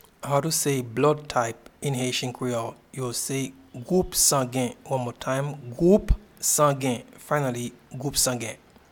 Pronunciation and Transcript:
Blood-type-in-Haitian-Creole-Gwoup-sangen.mp3